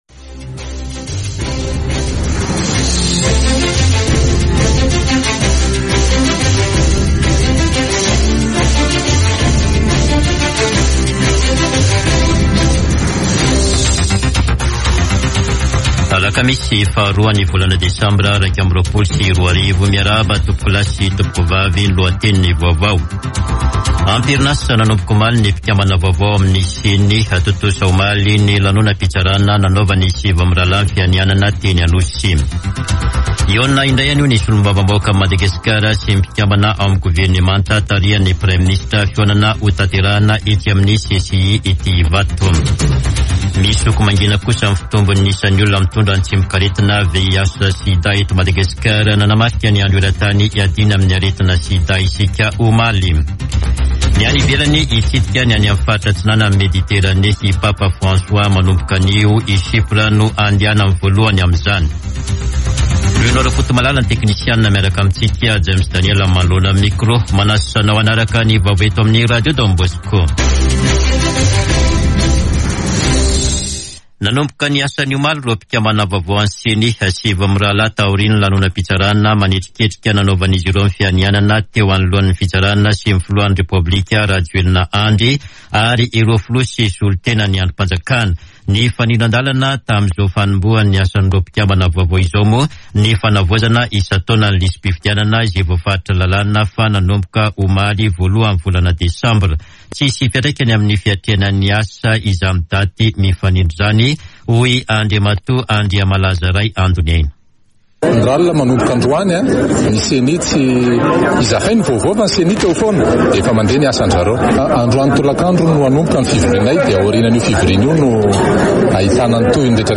[Vaovao maraina] Alakamisy 02 desambra 2021